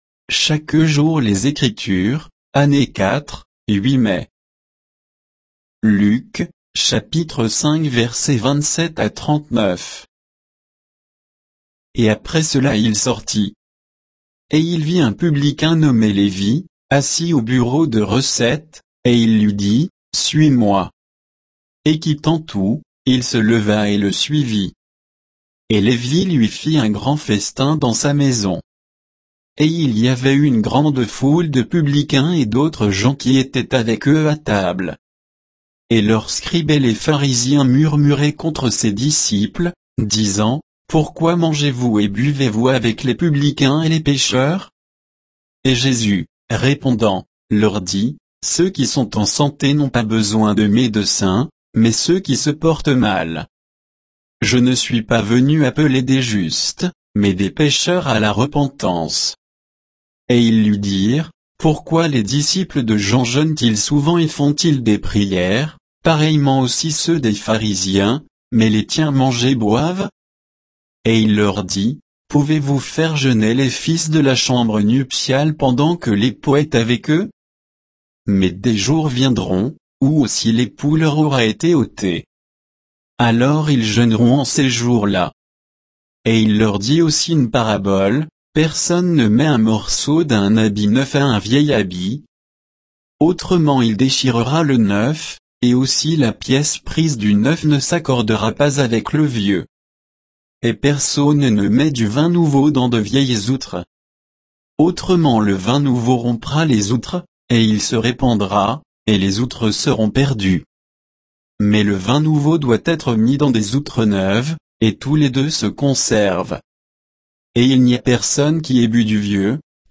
Méditation quoditienne de Chaque jour les Écritures sur Luc 5